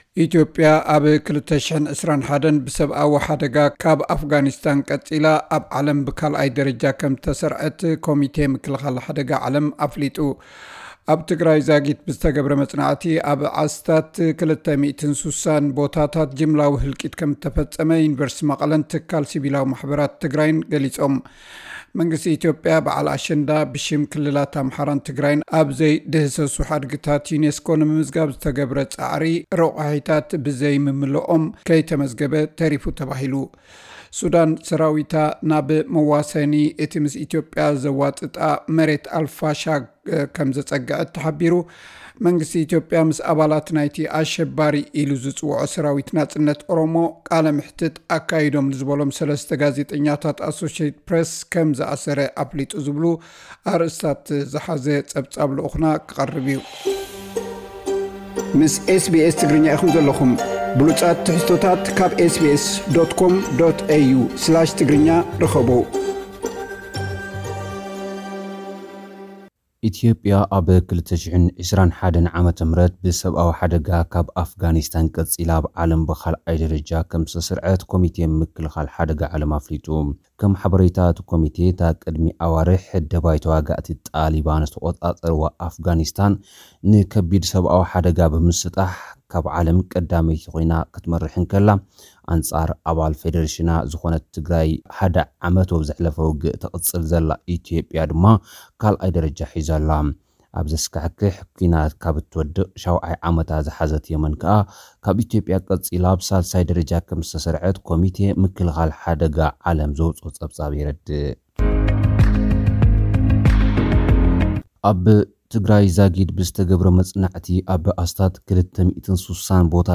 ኢትዮጵያ: ኣብ 2021 ብሰብኣዊ ሓደጋ ካብ ኣፍጋኒስታን ቀጺላ ኣብ ዓለም ብካልኣይ ደረጃ ከም ዝተሰርዐት ኮሚቴ ምክልኻል ሓደጋ ዓለም ኣፍሊጡ።(ካብ ኣርእስታት ጸብጻብ) | SBS Tigrinya